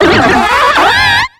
Cri de Mustéflott dans Pokémon X et Y.